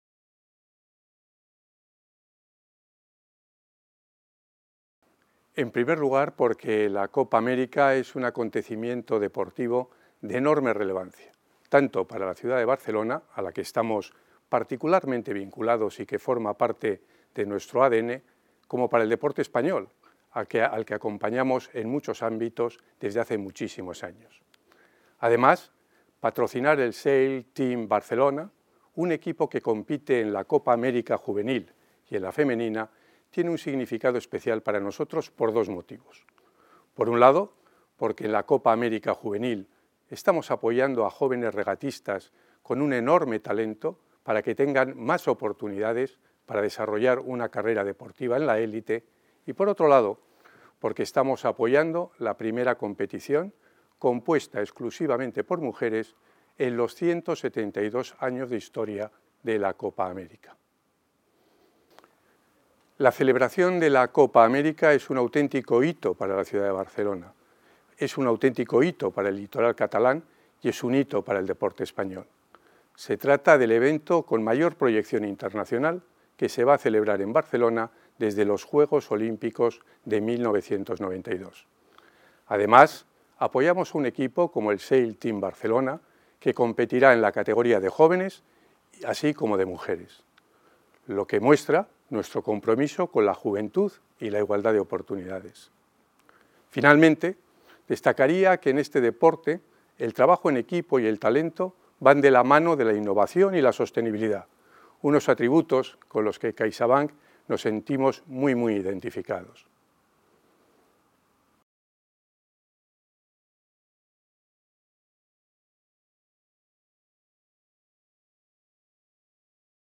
Audio - Declaraciones del presidente de CaixaBank